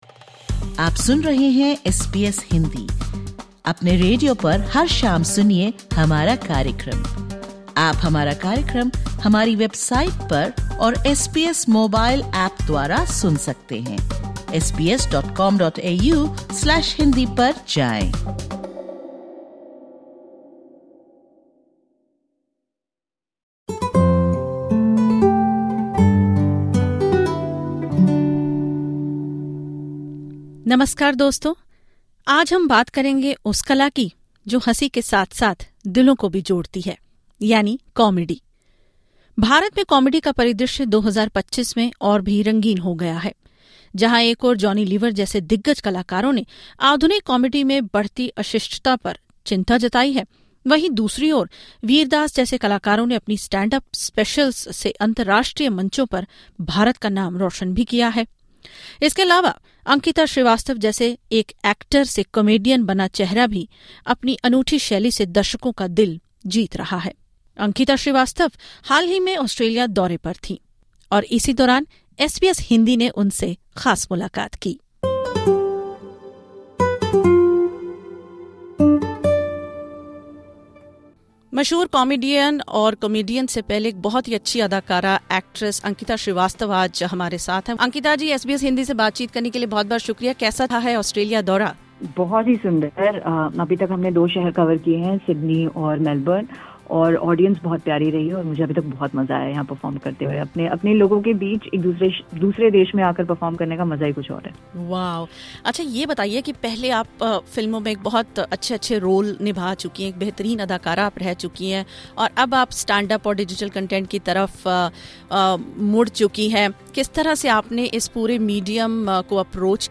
Enjoy this special conversation with her on SBS Hindi.